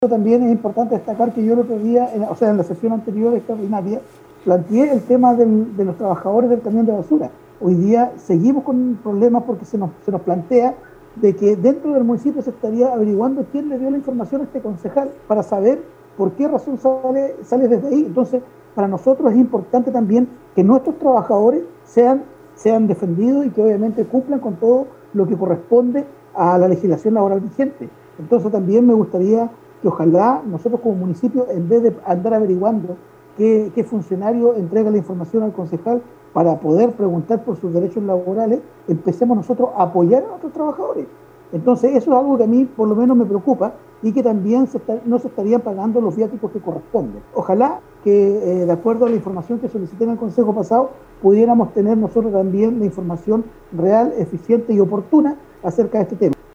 Este lunes, en sesión de Concejo Municipal en Ancud, se continuó analizando la situación del Relleno Sanitario de Puntra El Roble, ocasión en la que el concejal Andrés Ibáñez reiteró sus planteamientos en cuanto a la situación que afectaría a los trabajadores que deben salir de la provincia en los camiones compactadores, para disponer los residuos domiciliarios en la ciudad de Los Ángeles, región del BíoBío.
13-CONCEJAL-ANDRES-IBANEZ.mp3